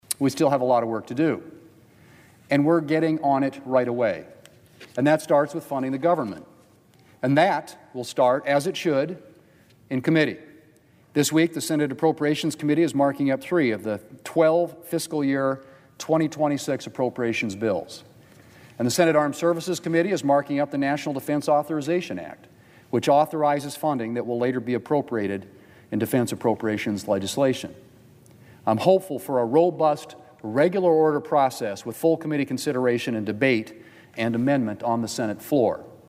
WASHINGTON, D.C.(HubCityRadio)- On Wednesday, Senate Majority Leader John Thune was on the floor of the U.S. Senate to reflect on the first six months of the session.